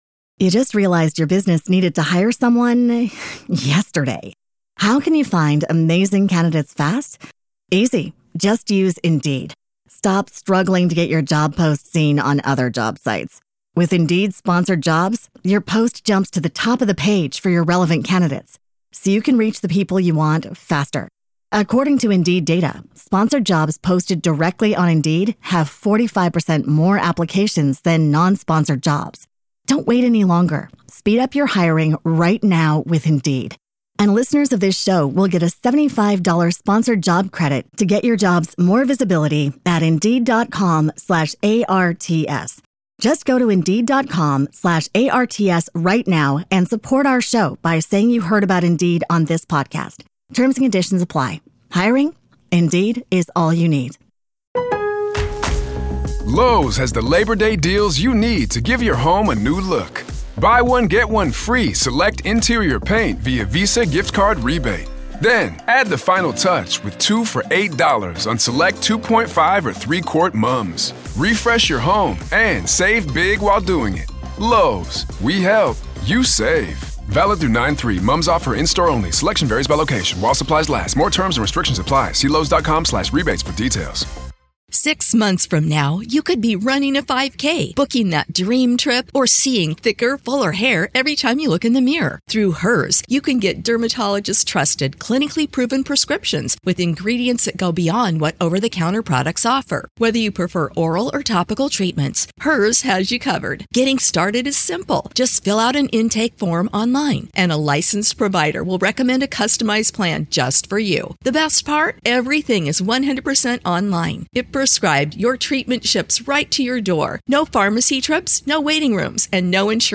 Nothing was scipted. All off the cuff as friends talk.